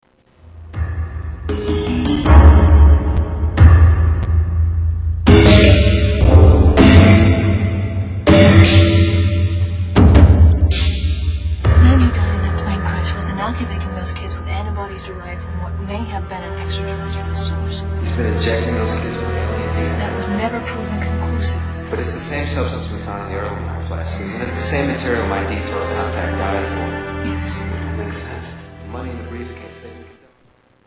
Category: Television   Right: Personal
Television Music